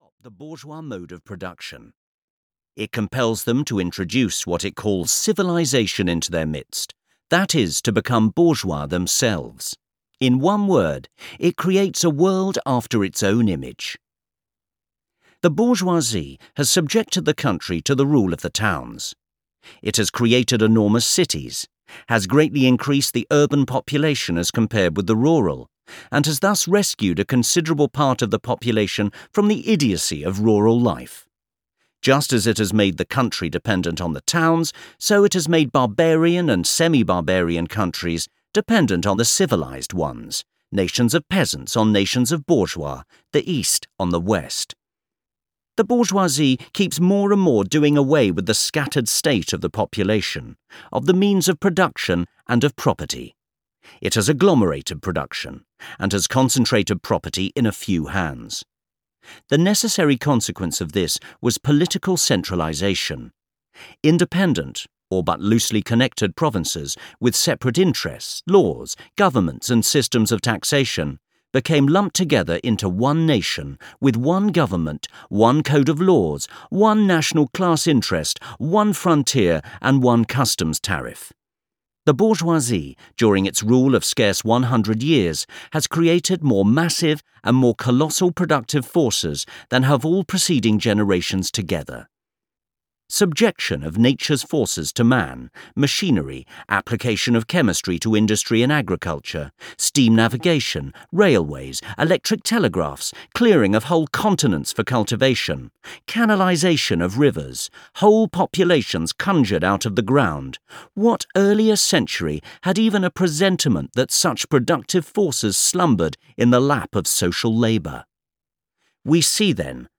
The Communist Manifesto (EN) audiokniha
Ukázka z knihy